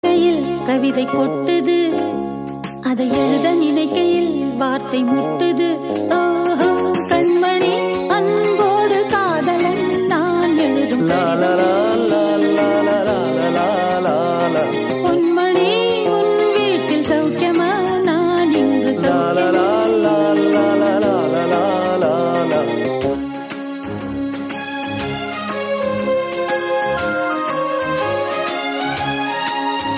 best flute ringtone download | love song ringtone